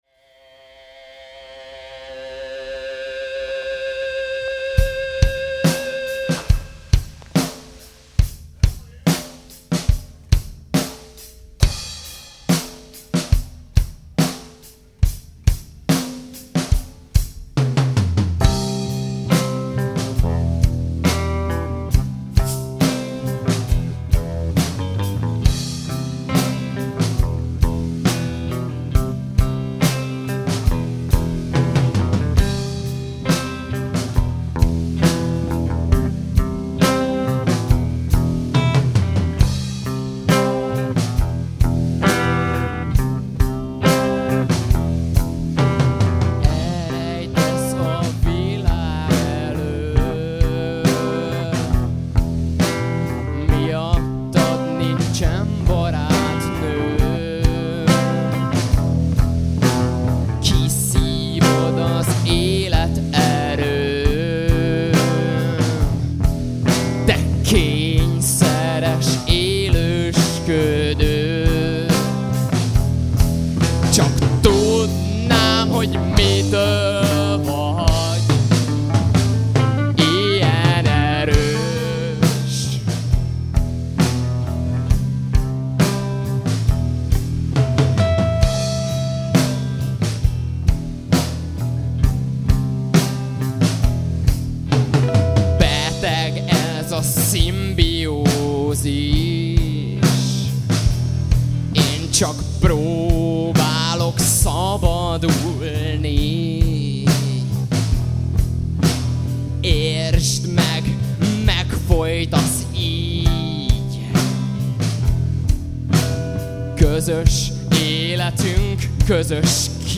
teátrális rockzenét játszik
glamrock